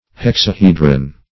Hexahedron \Hex`a*he"dron\, n.; pl. E. Hexahedrons, L.